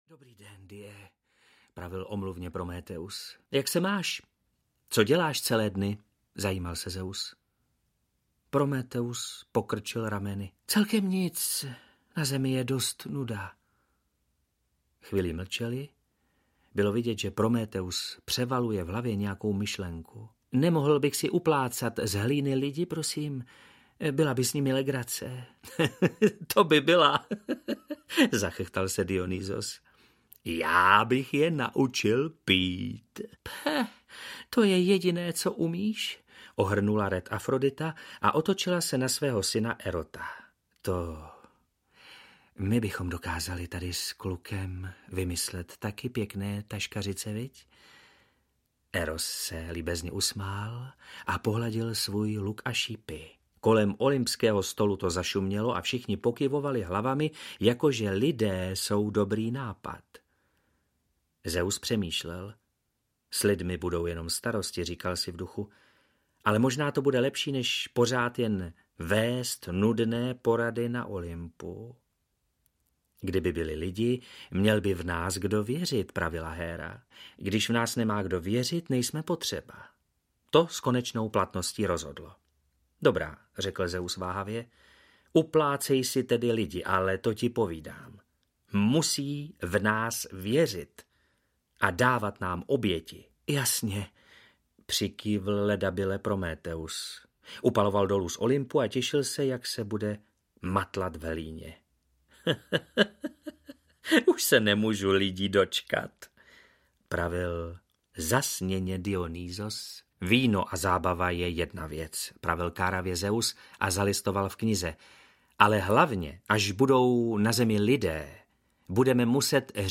Audio knihaStaré řecké báje a pověsti pro děti
Ukázka z knihy
• InterpretOndřej Kepka